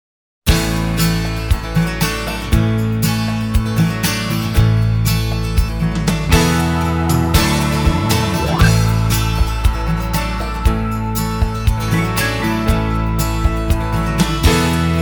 Country, Pop